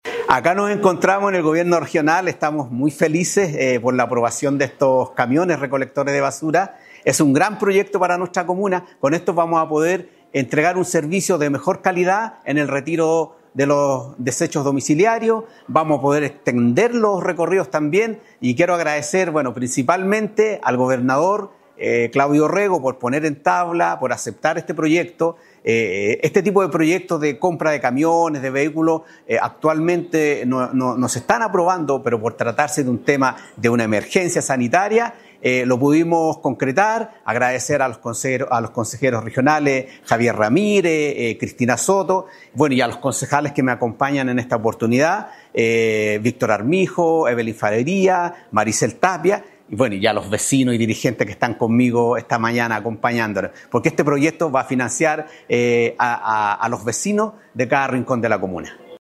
Finalmente el Alcalde de San Pedro, Manuel Devia, destacó la importancia de esta iniciativa “Acá nos encontramos en el gobierno regional, estamos muy felices por la aprobación de estos camiones recolectores de basura. Es un gran proyecto para nuestra comuna, con esto vamos a poder entregar un servicio de mejor calidad en el retiro de los desechos domiciliarios.
CUÑA-ALCALDE-CAMIONES-RSD.mp3